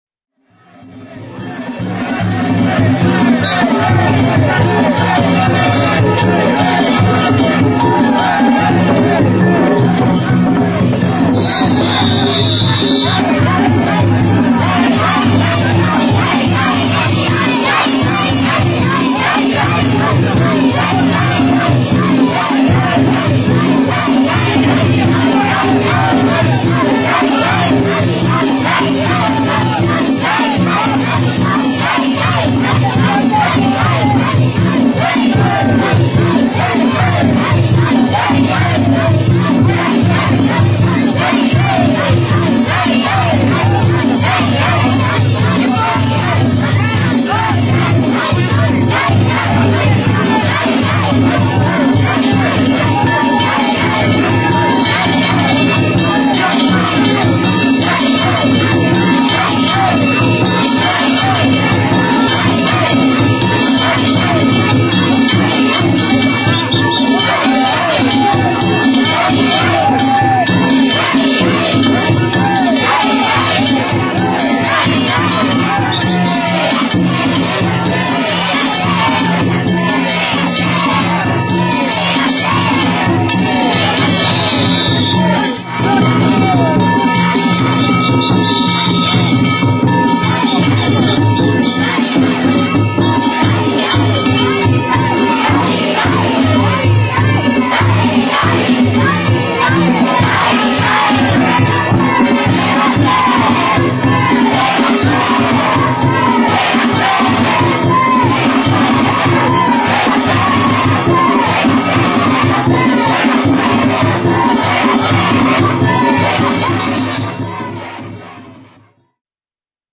平成２８年６月２６日、平野区の平野郷夏祭り前夜祭を見に行ってきました。
めっちゃ元気な声が聞こえると思ったら
鉦太鼓とガールズの声が混然となって
アーケード内に響き渡っています。